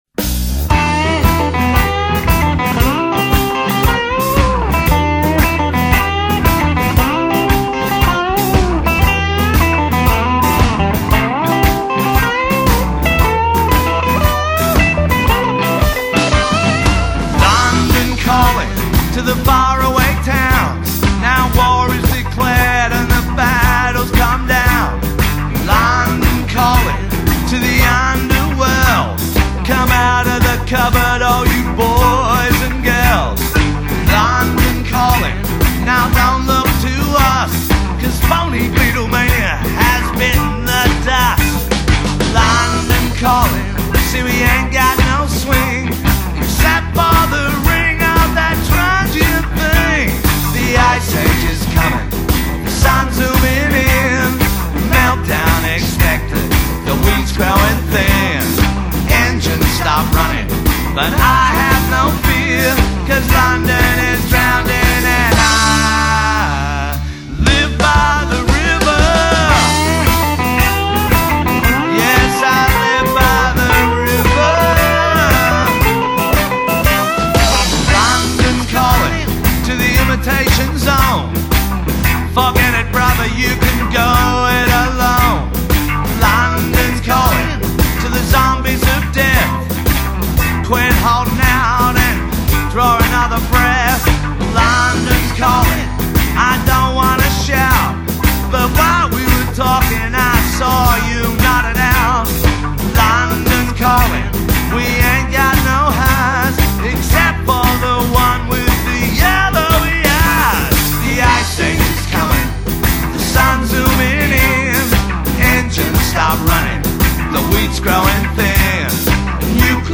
Rhythm and Blues